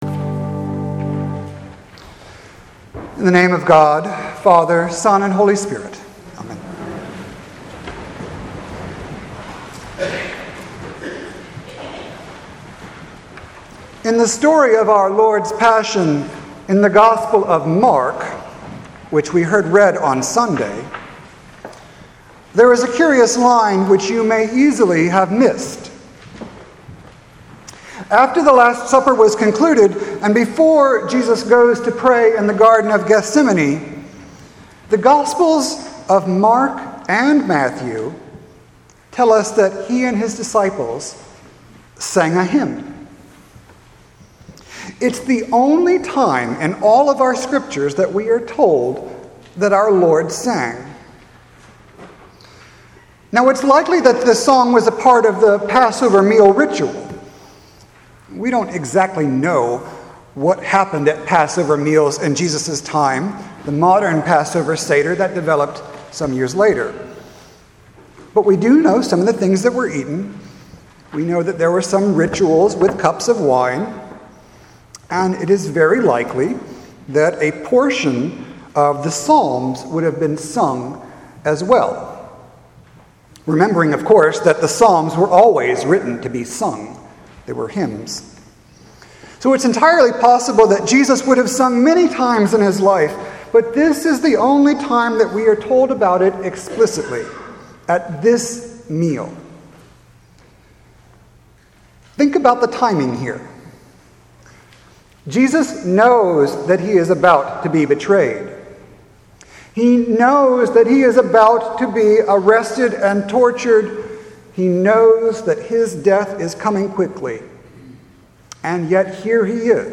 maundy-thursday-2018.mp3